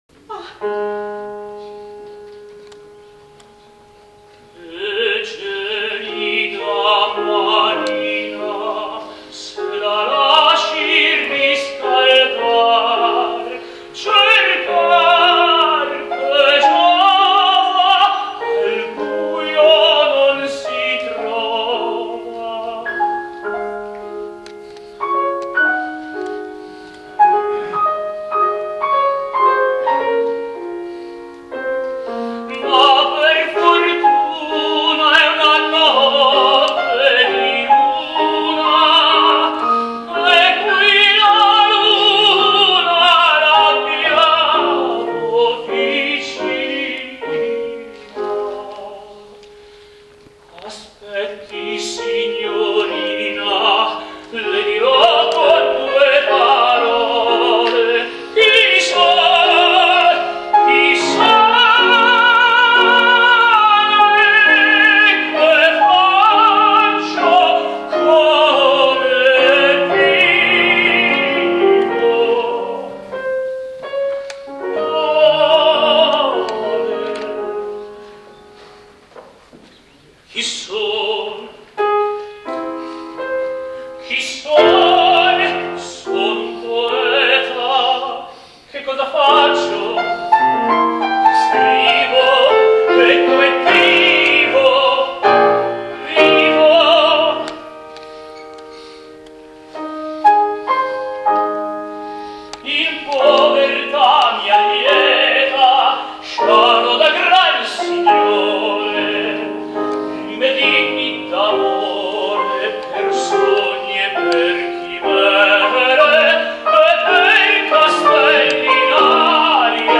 Cinema Teatro Odeon Vigevano